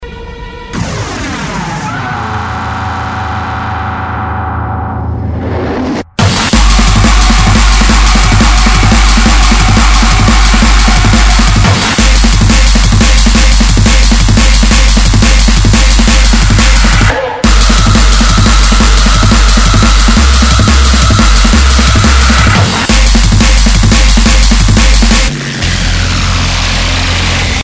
Drum'n'bass